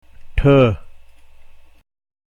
The Consonants
has no equivalent sound in English, but its sound is discernible as that of the letters TH in the word THUG (as in Hindi).